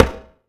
Perc 2 [ knock again ].wav